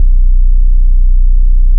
BASSS 10.wav